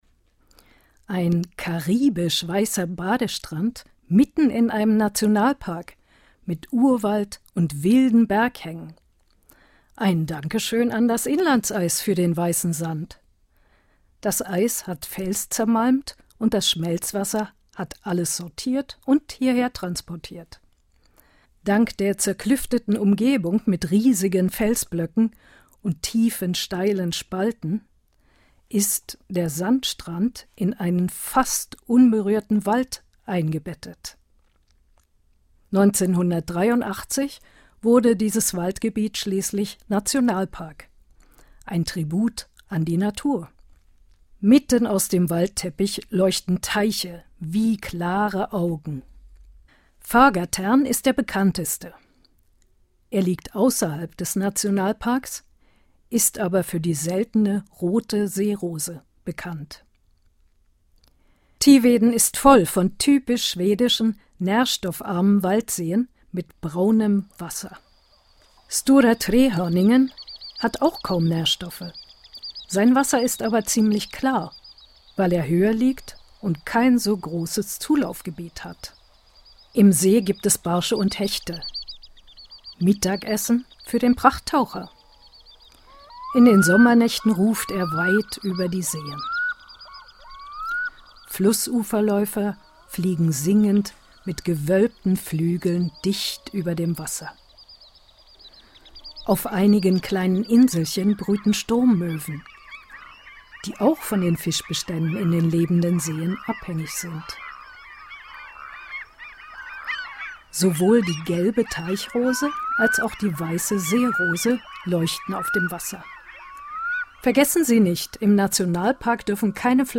Audio guides